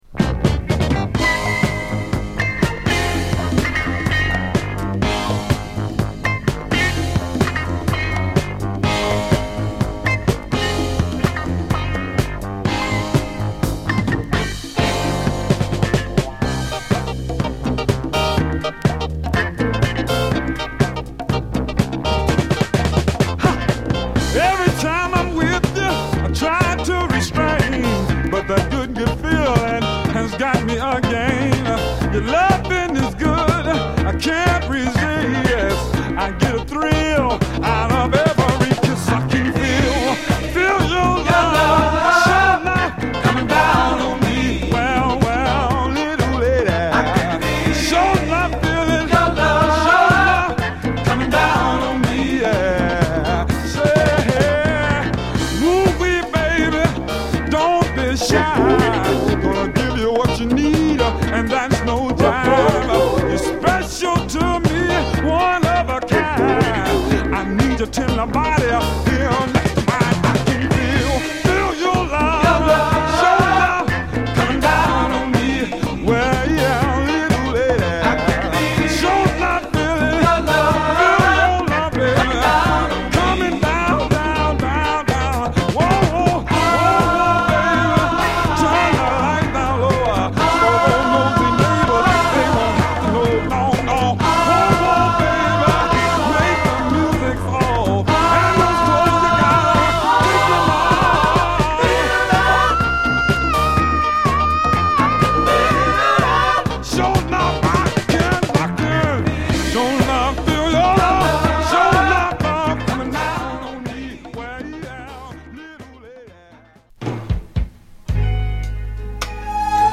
カンザスシティ発のスウィート・ソウル傑作！